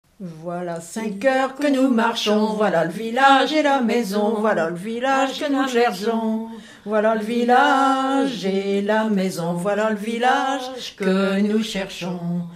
Mémoires et Patrimoines vivants - RaddO est une base de données d'archives iconographiques et sonores.
marche de retour de la messe de mariage, ici en duo
Genre énumérative
Pièce musicale inédite